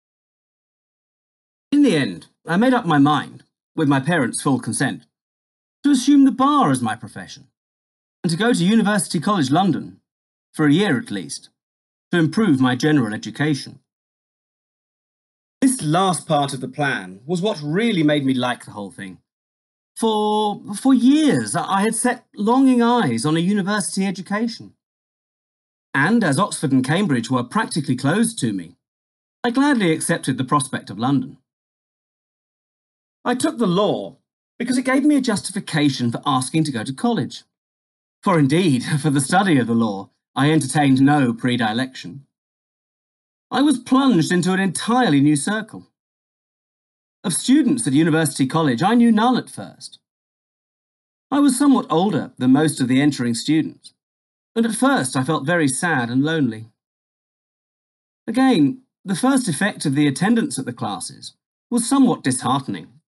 Edward Fry was excluded from Oxford and Cambridge because of his Quaker religion but enrolled at UCL in 1848 at the age of 21. This extract is taken from a memoir compiled posthumously by Fry’s daughter.